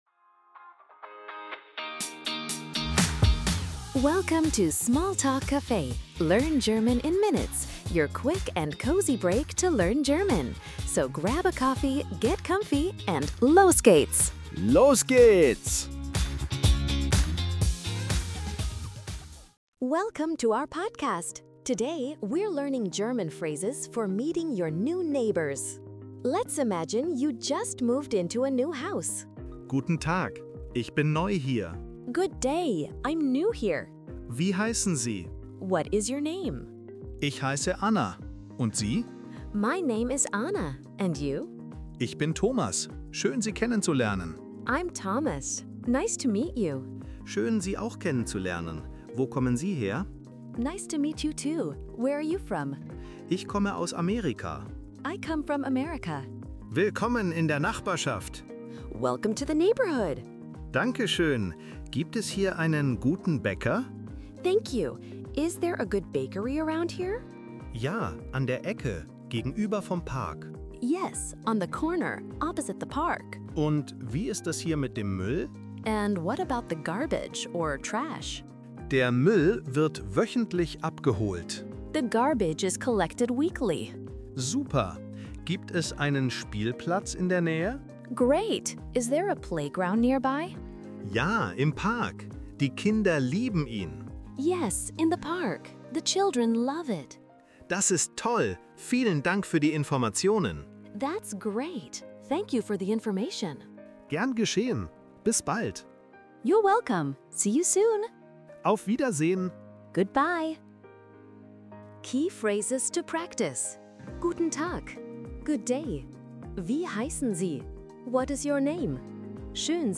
Tune in for quick, real-life dialogues, helpful tips, and the confidence boost you need to navigate daily errands in German!